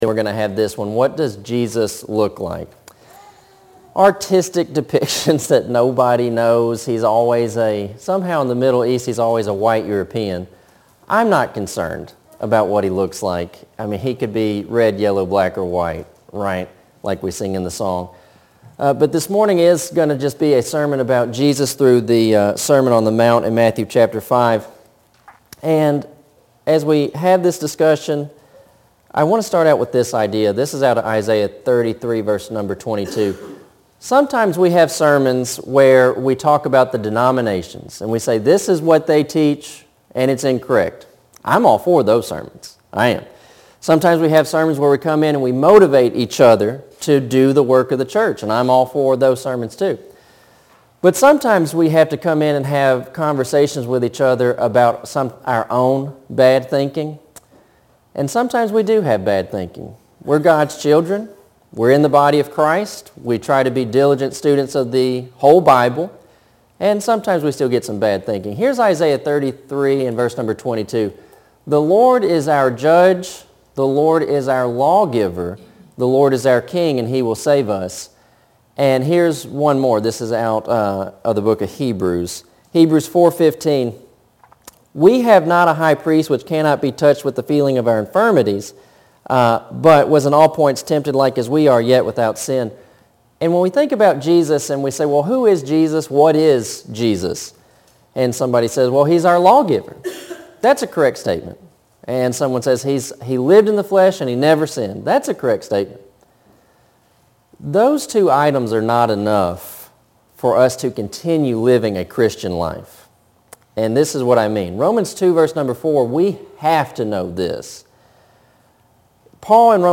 Sermon – Jesus Fulfilling the Law